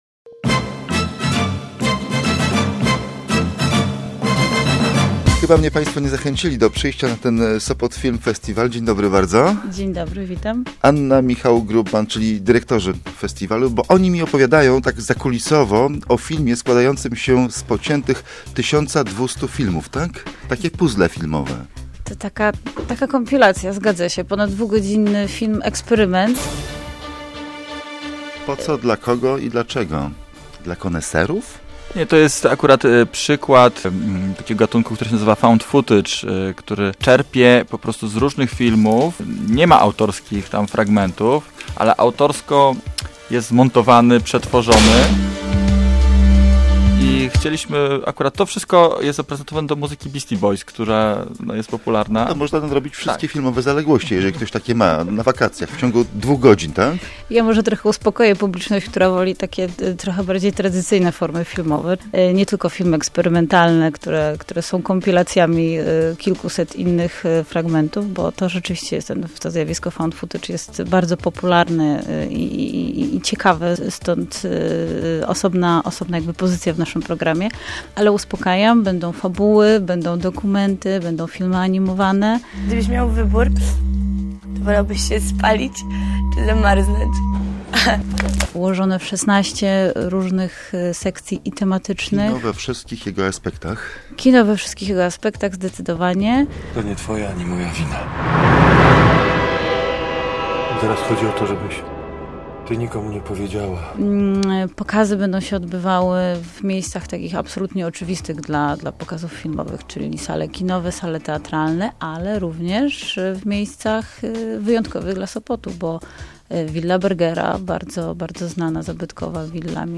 Posłuchaj rozmowy o SFP /audio/dok1/sopot-film.mp3